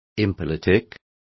Complete with pronunciation of the translation of impolitic.